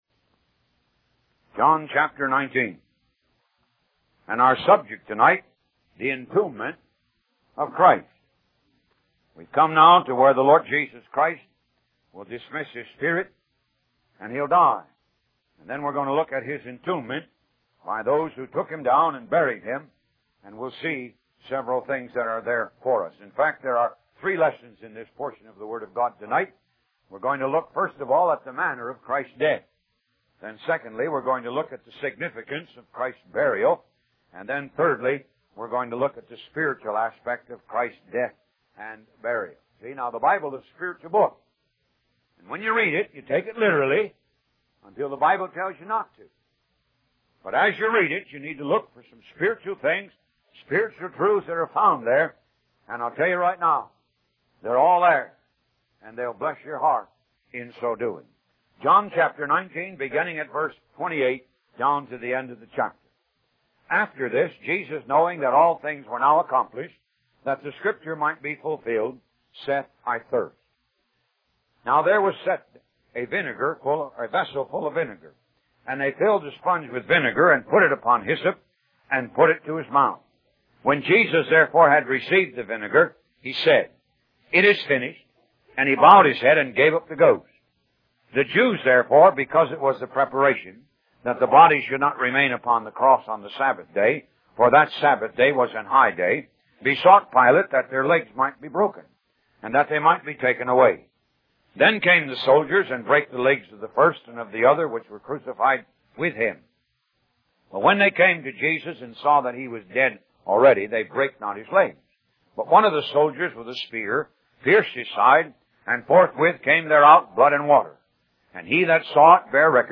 Talk Show Episode, Audio Podcast, Moga - Mercies Of God Association and The Entombment Of Christ on , show guests , about The Entombment Of Christ, categorized as Health & Lifestyle,History,Love & Relationships,Philosophy,Psychology,Christianity,Inspirational,Motivational,Society and Culture